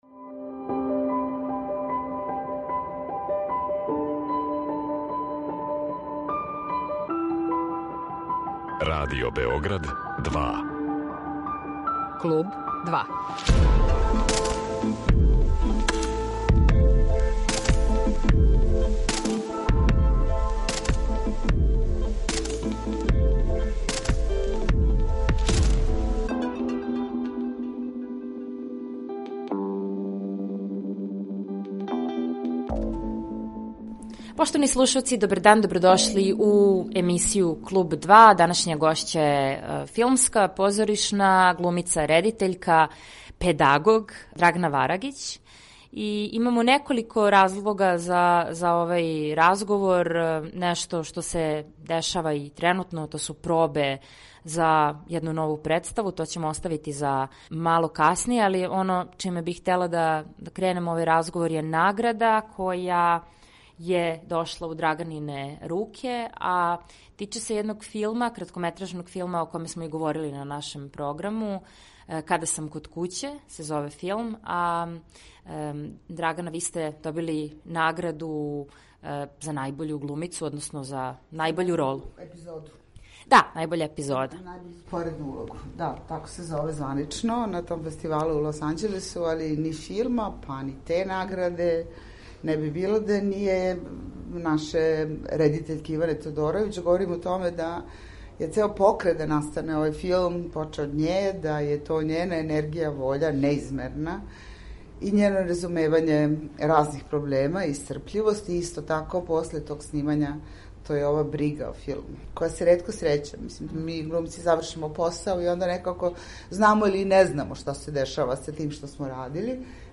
Гошћа данашње емисије је позоришна и филмска глумица и редитељка Драгана Варагић.